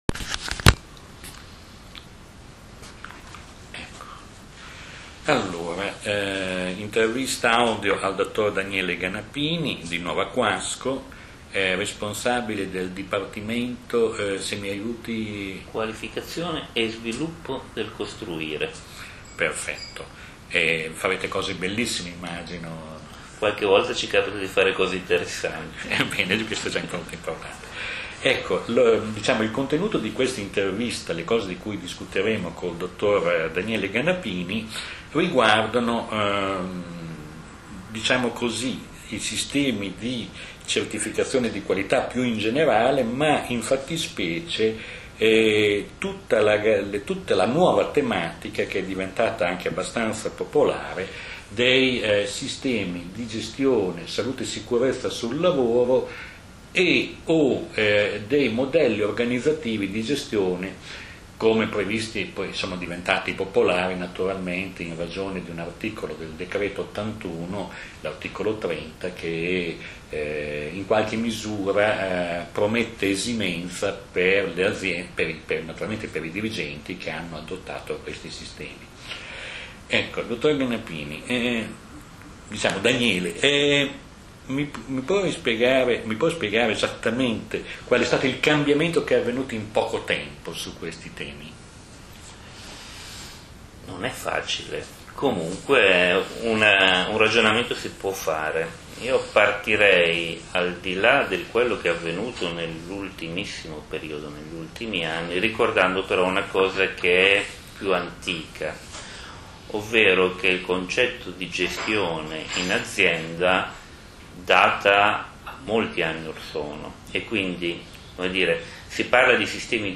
Intervista Audio 30 minuti